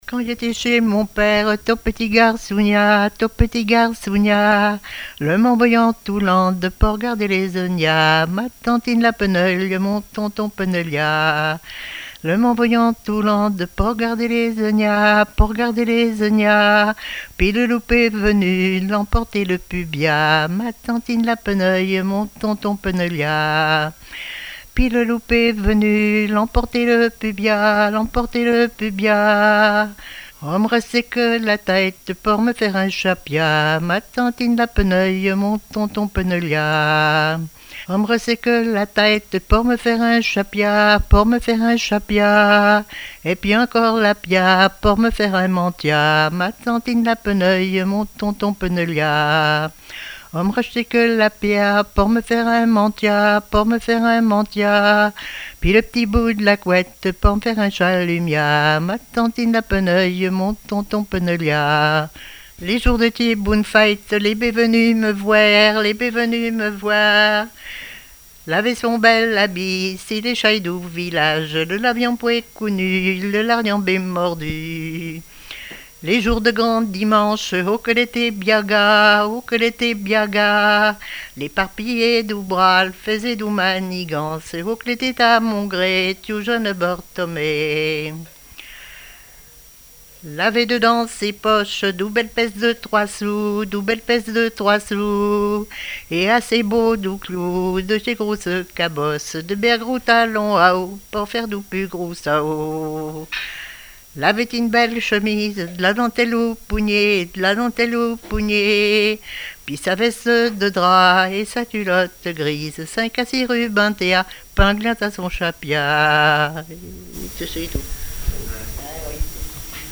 Genre laisse
répertoire de chansons et témoignages
Pièce musicale inédite